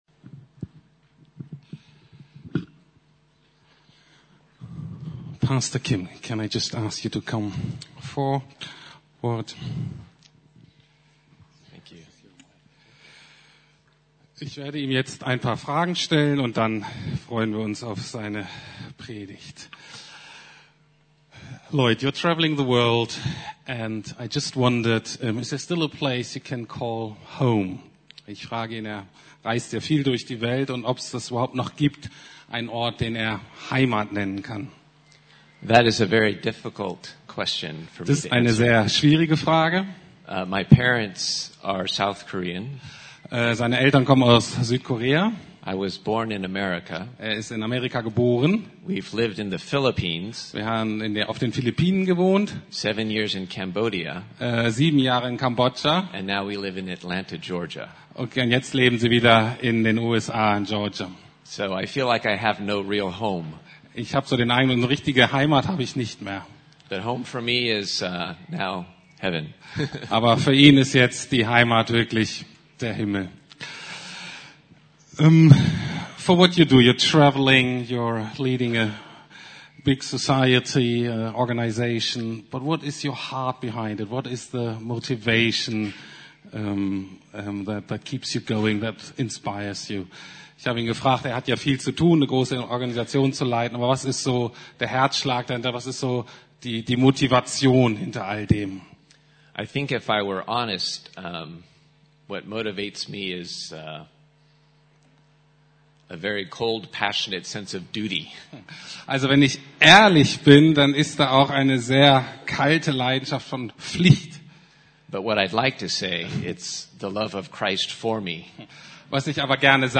Missionsbefehl der Gnade ~ Predigten der LUKAS GEMEINDE Podcast